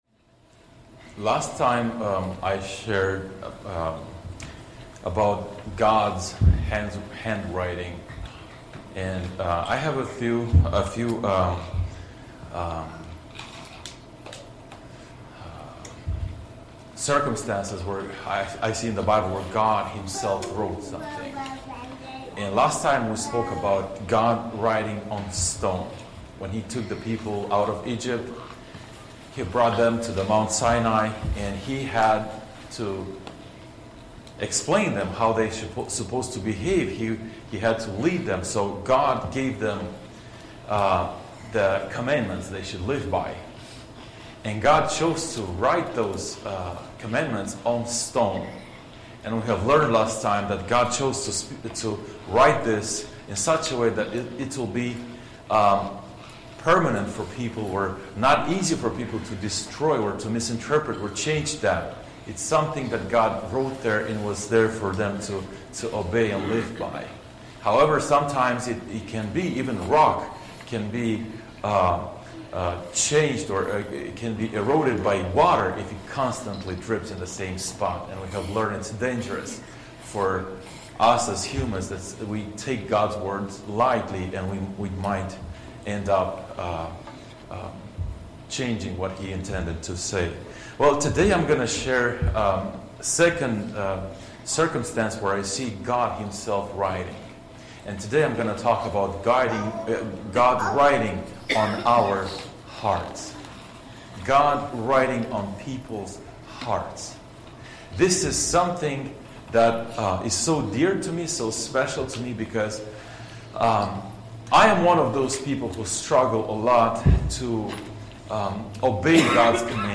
Teaching For March 23, 2024